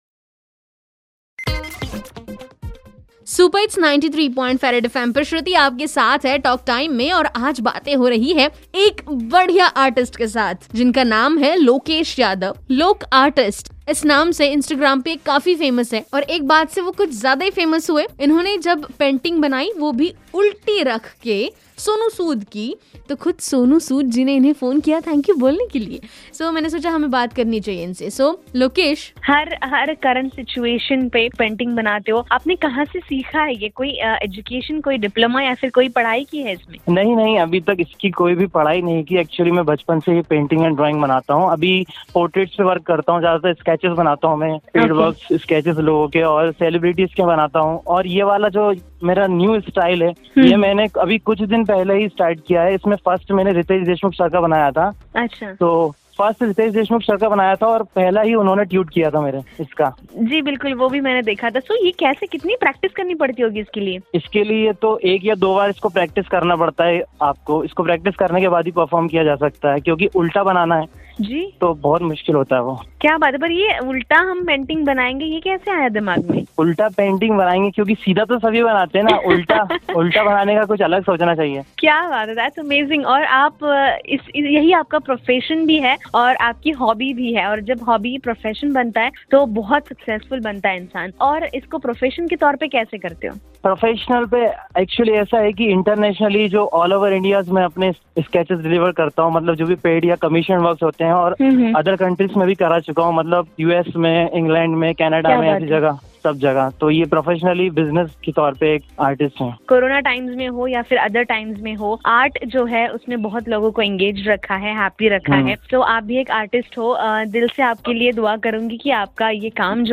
INTERVIEW PART - 2